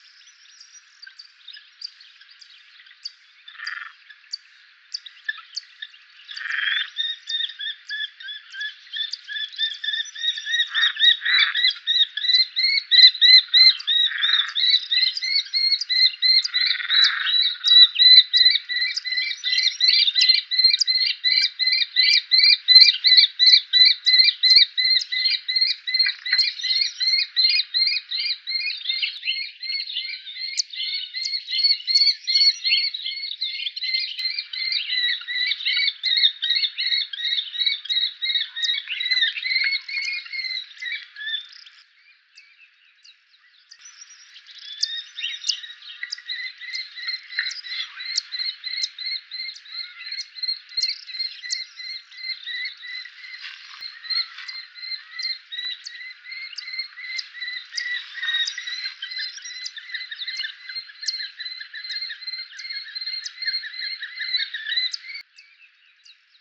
FALCO TINNUNCULUS - KESTREL - GHEPPIO
- COMMENT: Their prolonged and excited vocal performance is likely a courtship display (possibly culminating in copulation).
The original recording length was slightly shortened (see the spectrogram's time axis). Background: Zitting Cisticola, Italian Sparrow - MIC: (P)